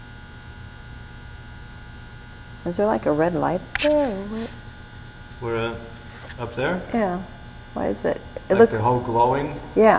"Warehouse" Singing EVP
right after she says "red light" something sings right into the mic; not any of us!